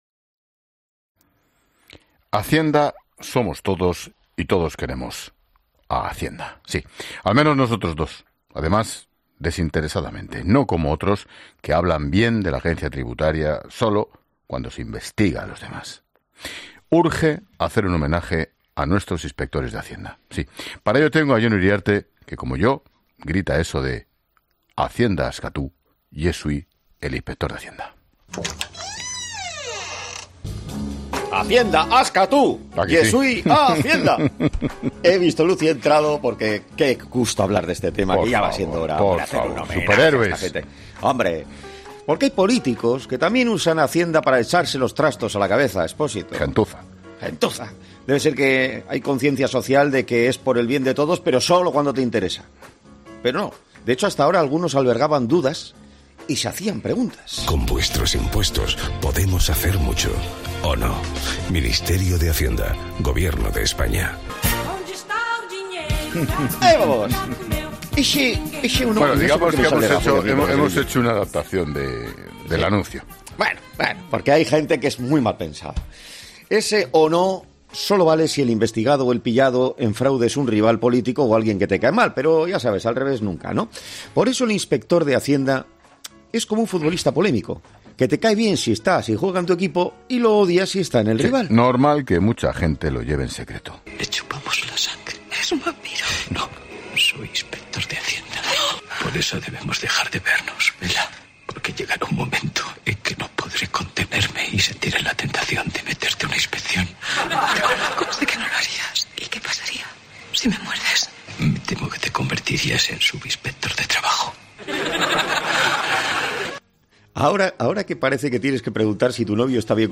El director de La Linterna, Ángel Expósito, y el comunicador, Jon Uriarte, hablan sobre los inspectores de hacienda